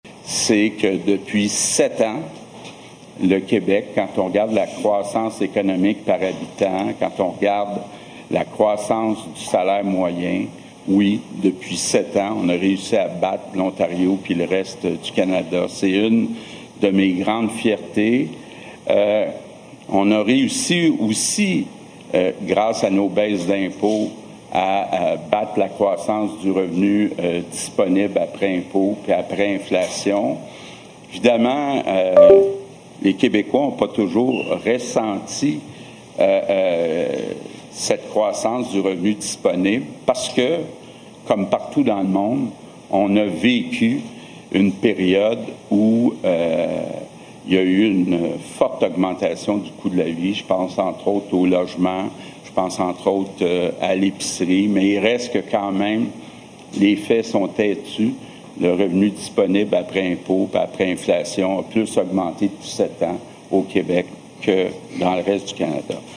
M. Legault a tenu une conférence de presse à Québec, à l’édifice Honoré-Mercier.